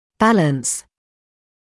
[‘bæləns][‘бэлэнс]равновесие; баланс; гармоничное состояние; уравновешивать; сопоставлять; обдумывать